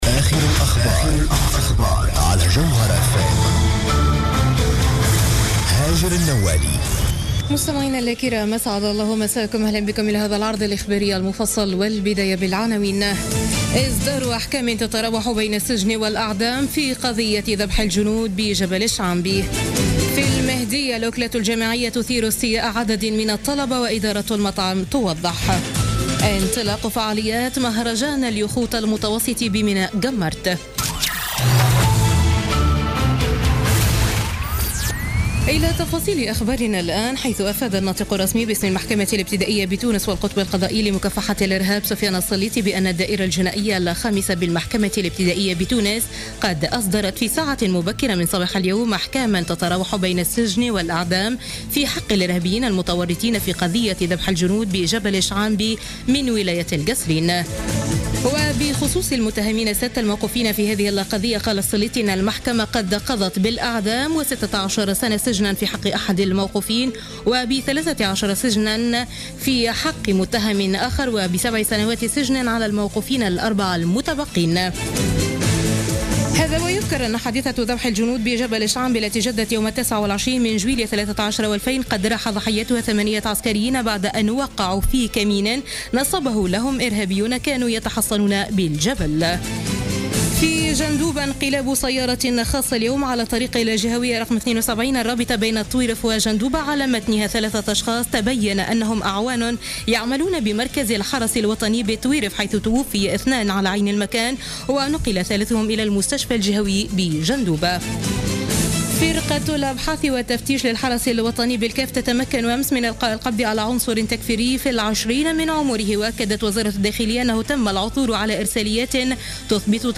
نشرة أخبار السابعة مساء ليوم الأربعاء 12 أكتوبر 2016